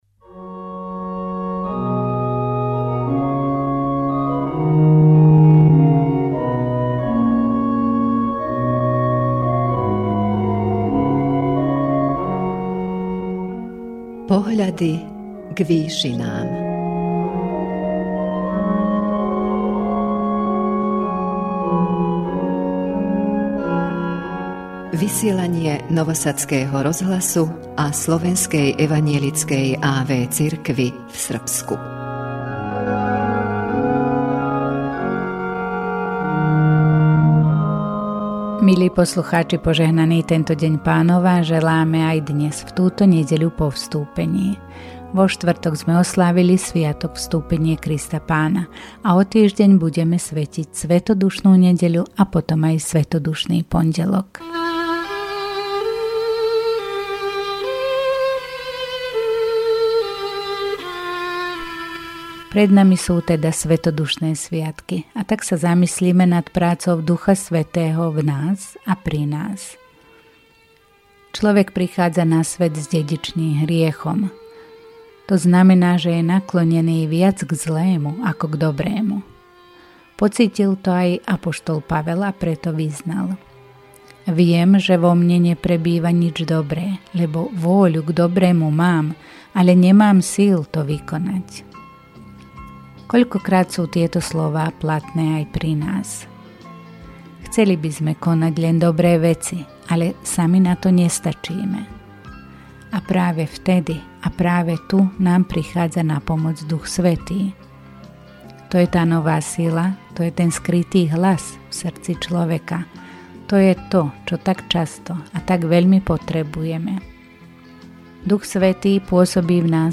V duchovnej relácii Pohľady k výšinám Rádia Nový Sad a Slovenskej evanjelickej a.v. cirkvi v Srbsku v túto Nedeľu po Vstúpení s duchovnou úvahou sa